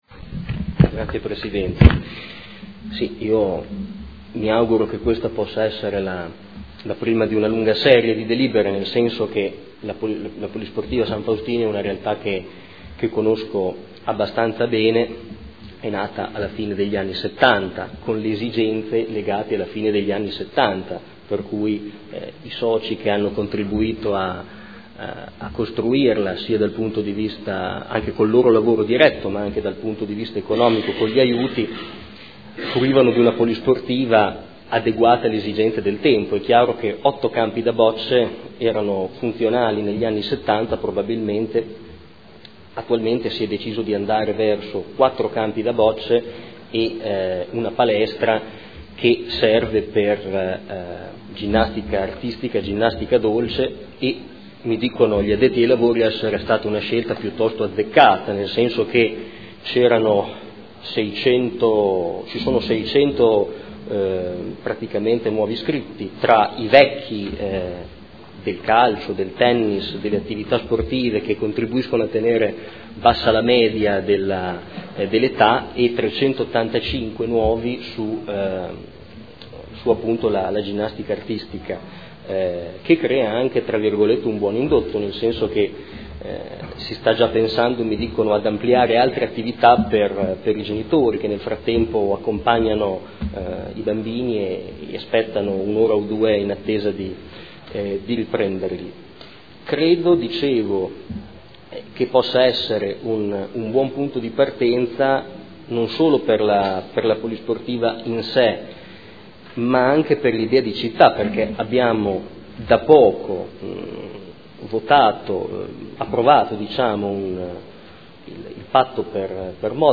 Marco Forghieri — Sito Audio Consiglio Comunale